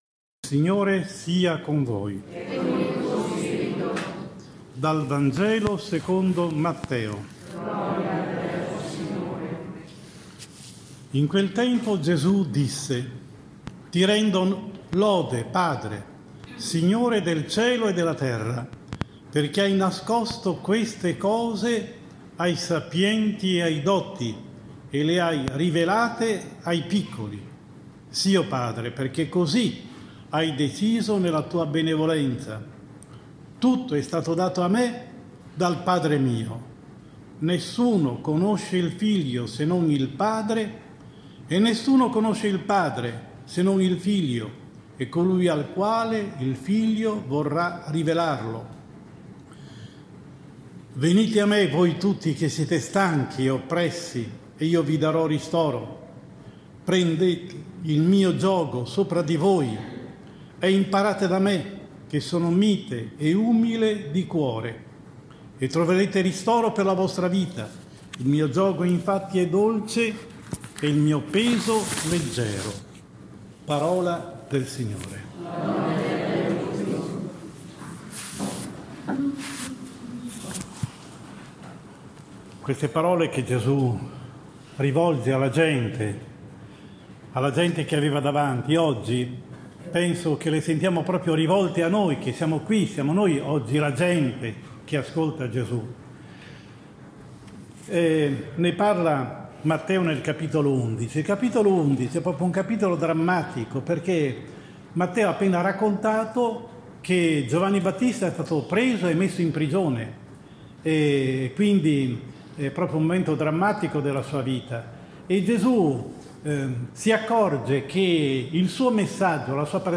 5 luglio 2020 XIV DOMENICA DEL TEMPO ORDINARIO (ANNO A) – omelia